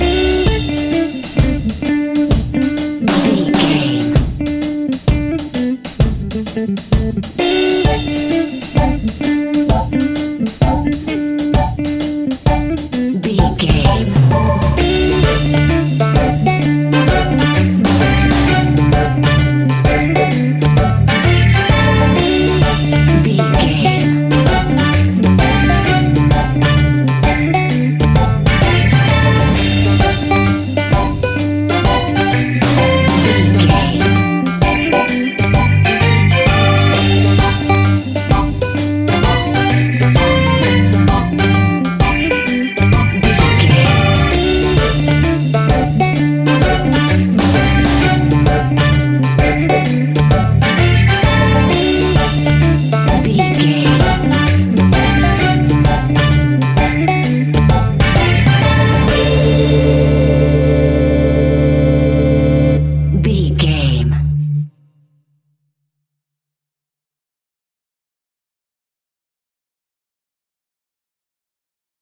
Ionian/Major
D
laid back
chilled
off beat
drums
skank guitar
hammond organ
percussion
horns